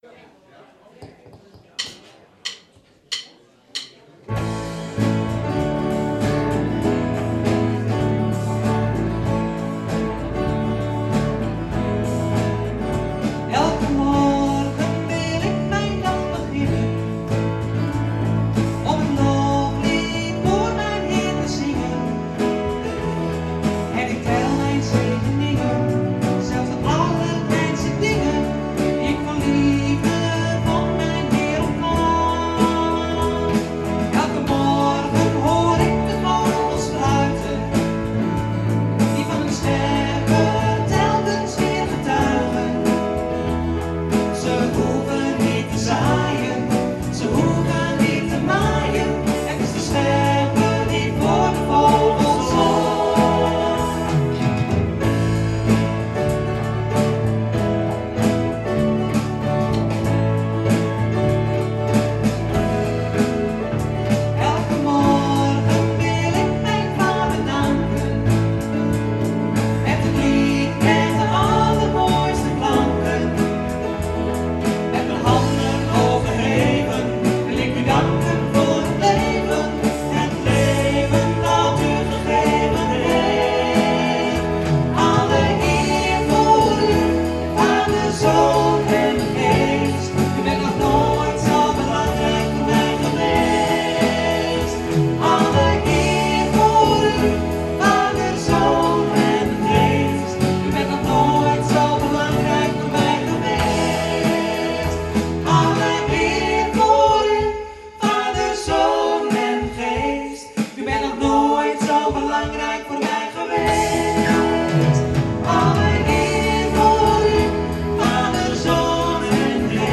Schriftlezing: Prediker 8: 9 - 15 en prediker 9: 1 - 3 en 7 - 10